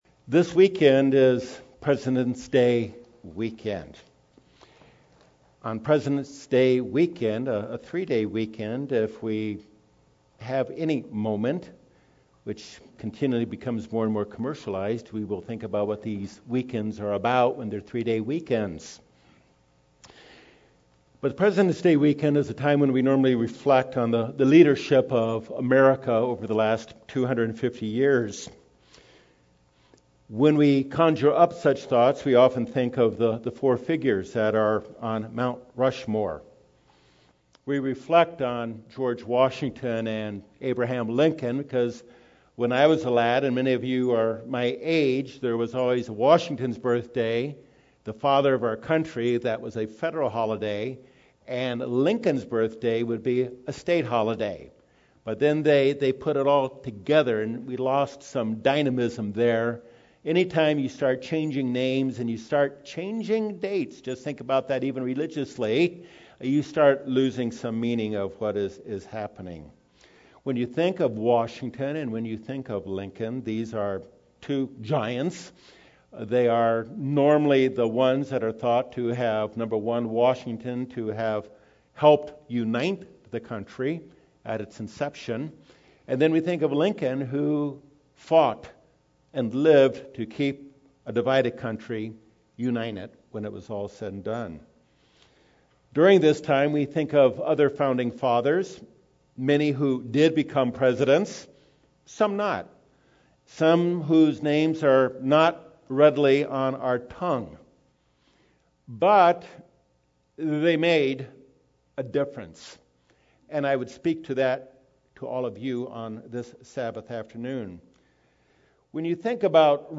This message given on Presidents Day Weekend focuses on a man who brought two giants of American History back together after years of friction.